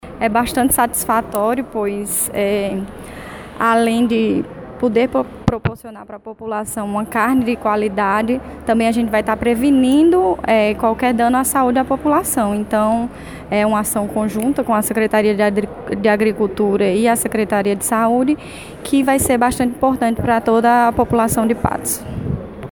Fala da secretária municipal de Saúde, Andressa Lopes –